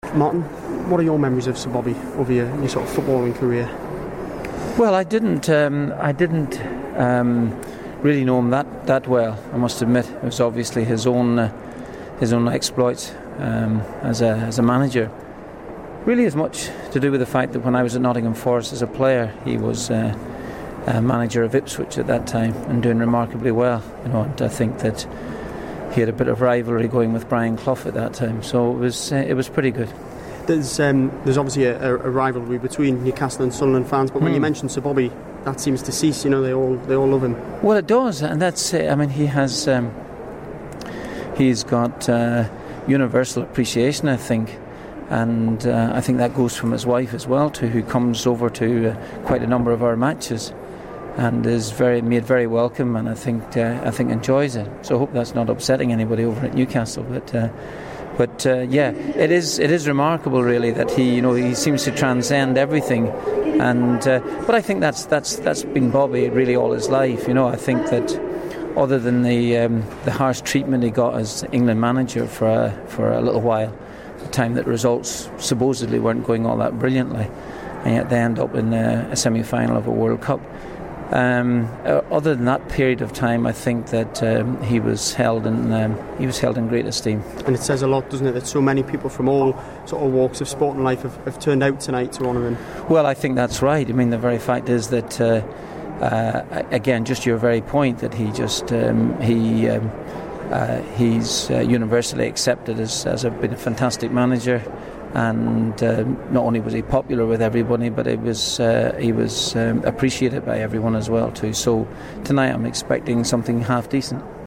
My chat with the Sunderland manager for Metro Radio News